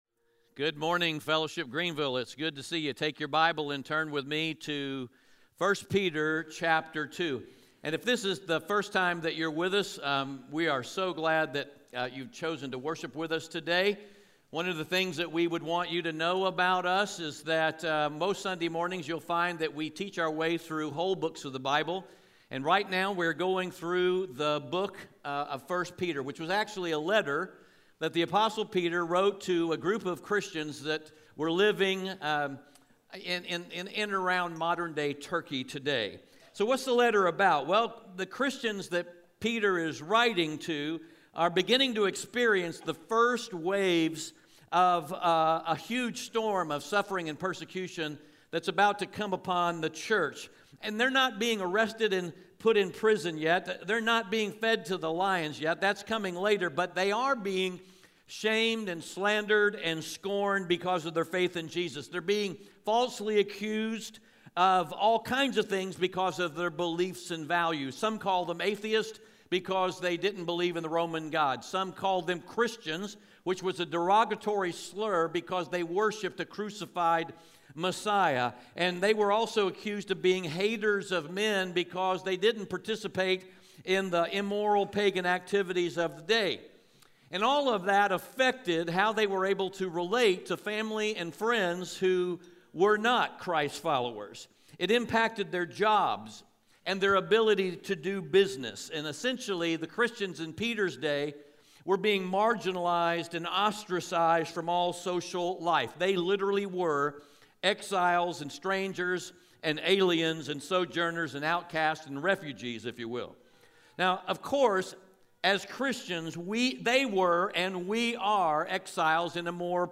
1 Peter 2:11-12 Audio Sermon